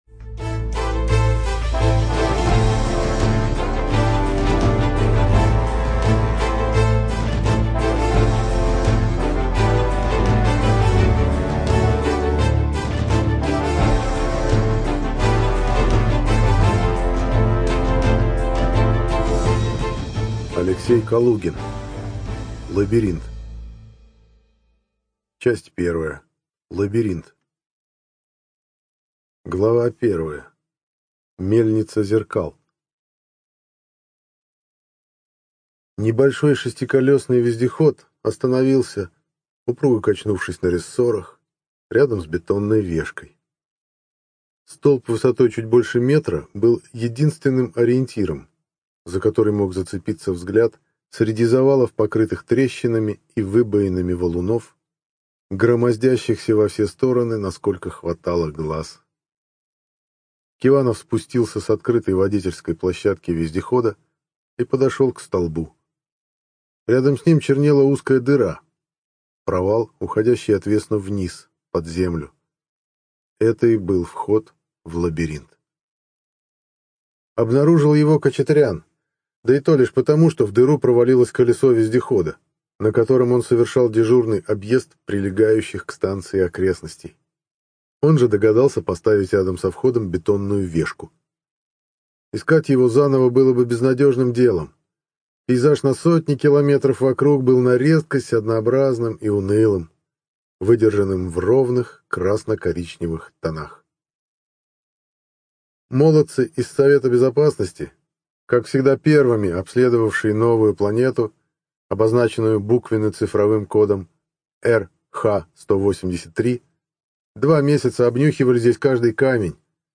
ЖанрФантастика, Боевики